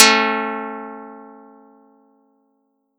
Audacity_pluck_4_13.wav